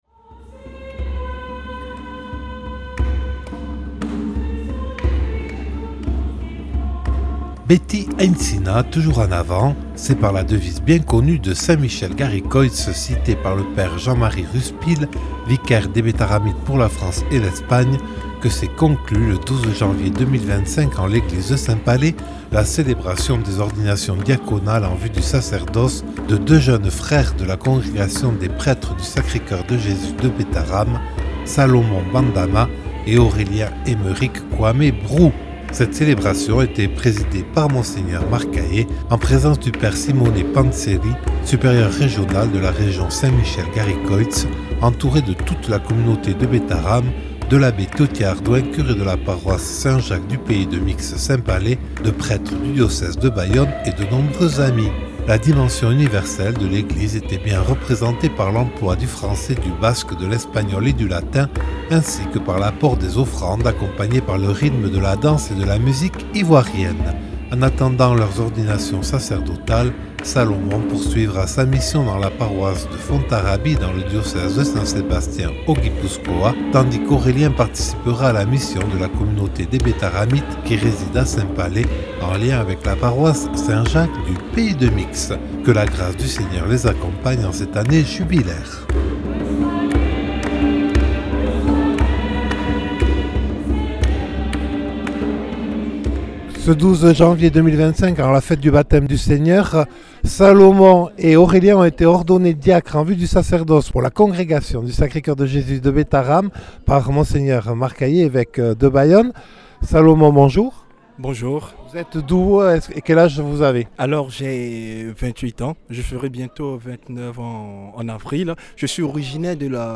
Deux diacres en vue du sacerdoce ordonnés en l’église de Saint-Palais